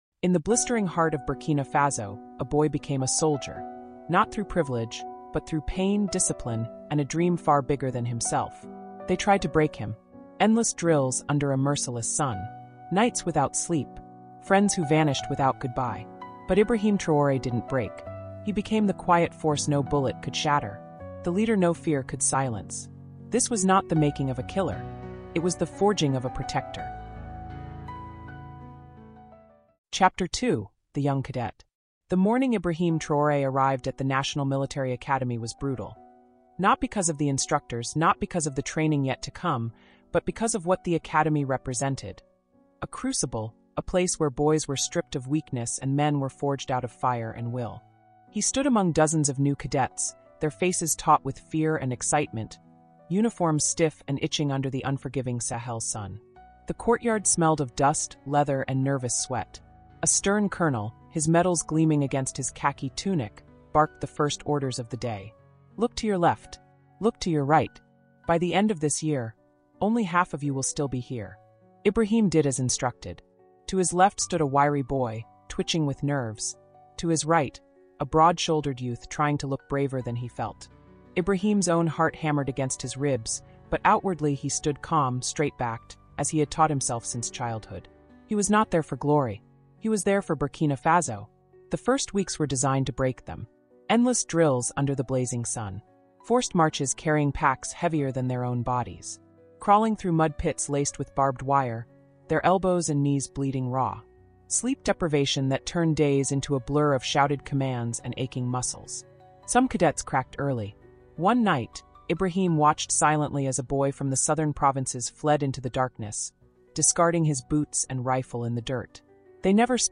Captain Ibrahim Traoré: africa cultural diplomacy (ch2) | Audiobook